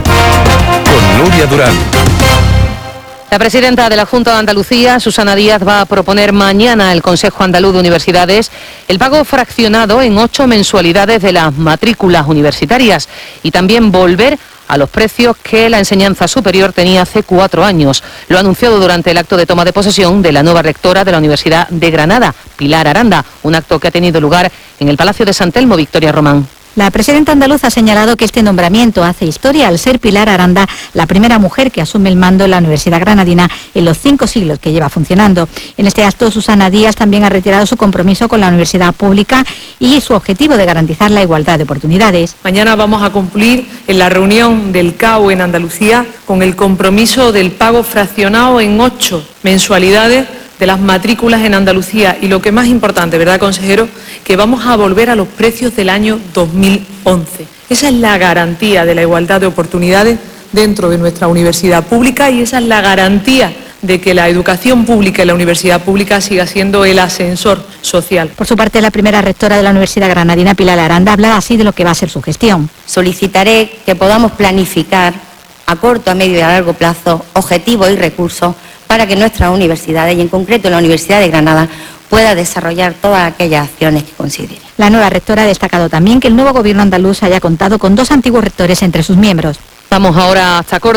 La presidenta de la Junta de Andalucía, Susana Díaz, que ha presidido hoy la toma de posesión de la nueva rectora de la Universidad de Granada, Pilar Aranda, ha anunciado hoy que el Gobierno regional llevará mañana al Consejo Andaluz de Universidades (CAU) una propuesta para acordar el pago fraccionado de la matrícula en ocho mensualidades con una rebaja para reducir los precios a niveles de 2011. Decl. Susana Díaz.